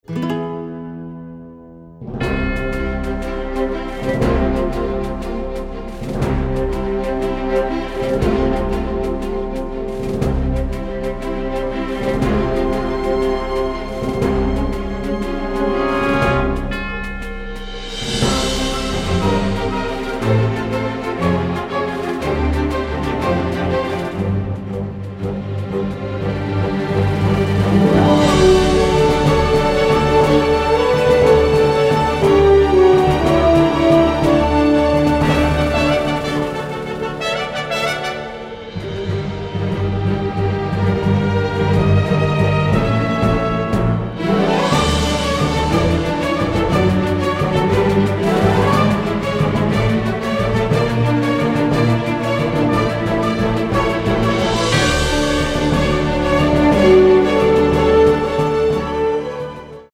symphonic, varied, funny and emotional